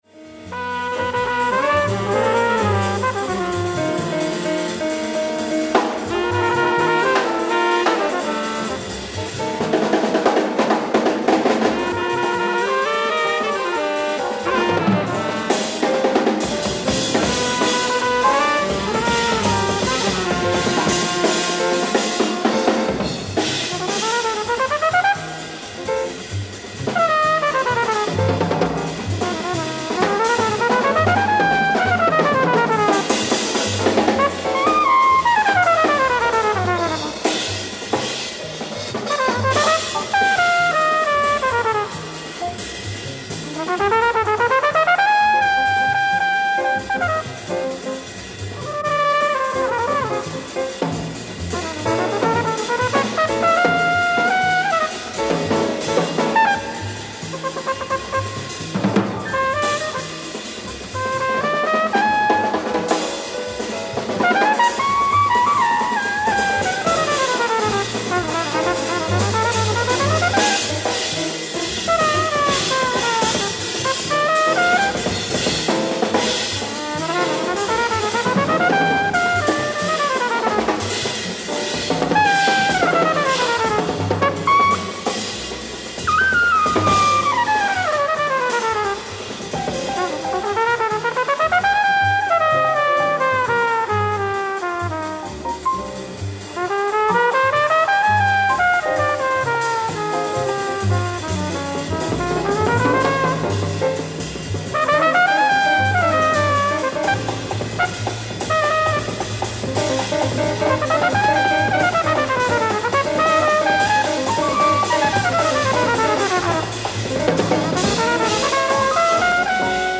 Live At Konserthuset, Stockholm, Sweden 10/31/1967